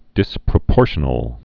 (dĭsprə-pôrshə-nəl)